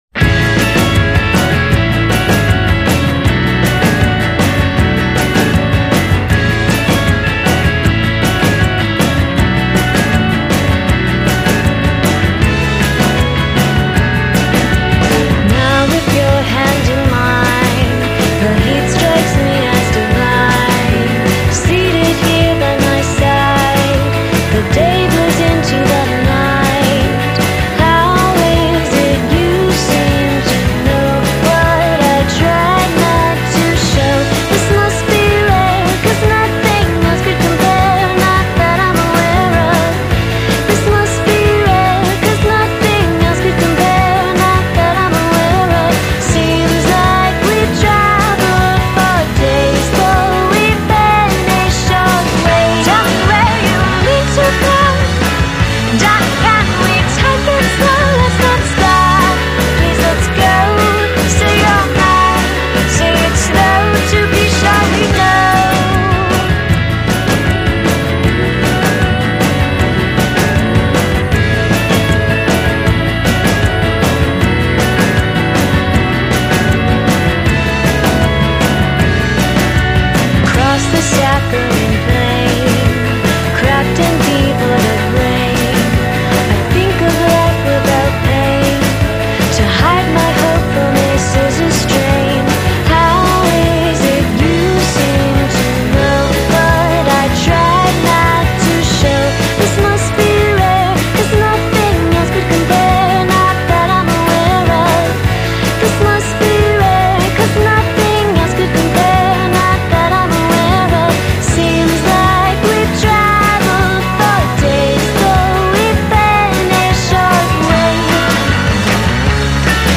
indiepop
è puro Cardigans del 1994